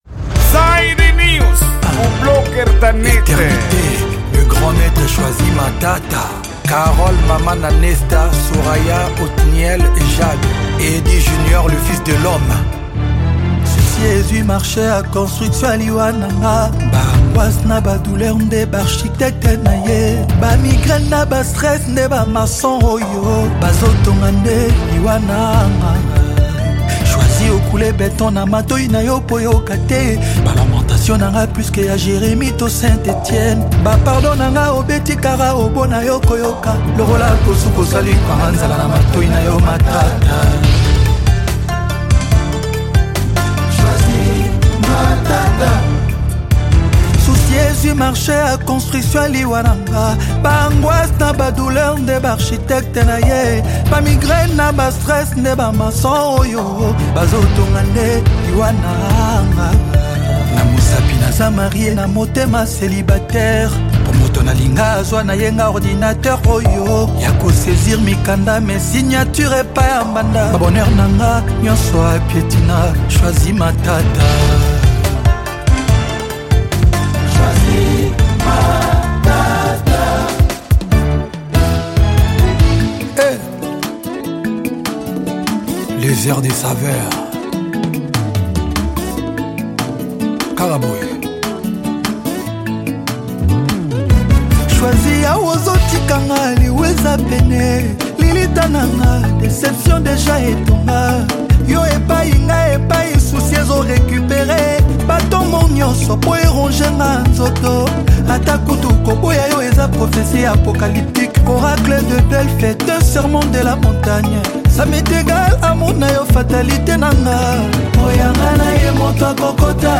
Gênero:Rumba